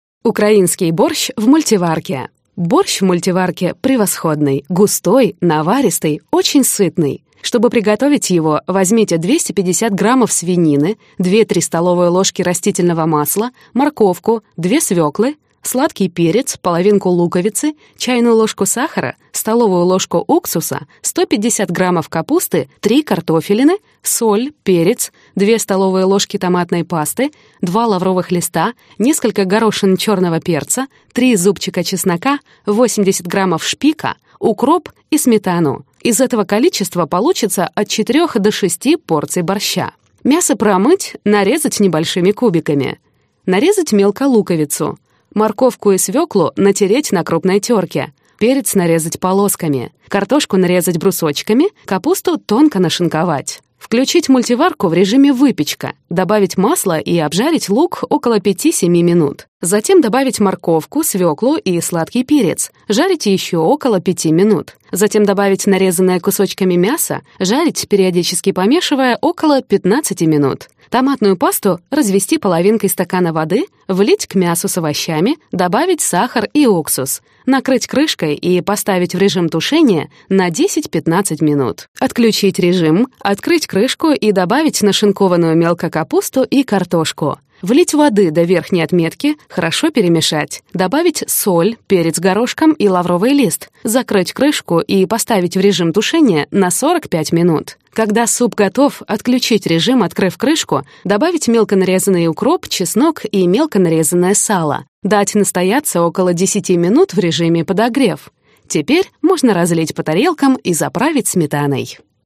Аудиокнига Рецепты для мультиварки | Библиотека аудиокниг
Прослушать и бесплатно скачать фрагмент аудиокниги